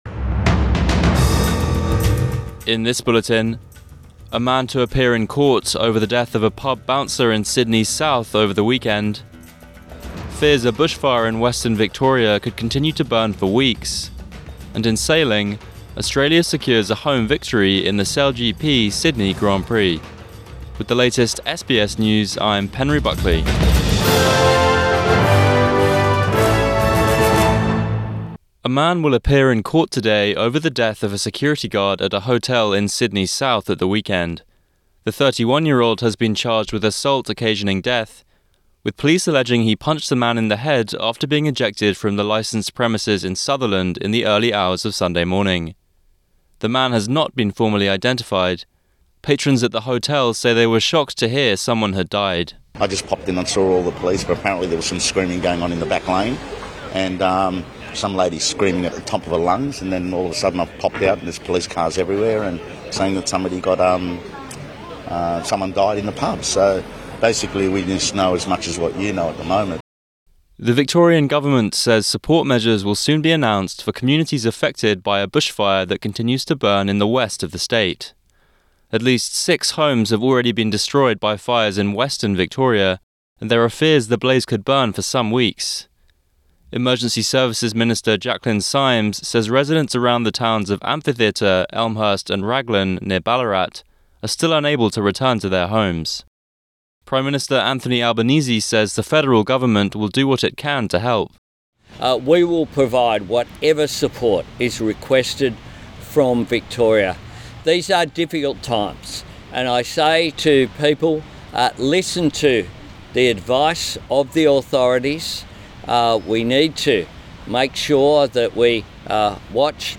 Morning News Bulletin 26 February 2024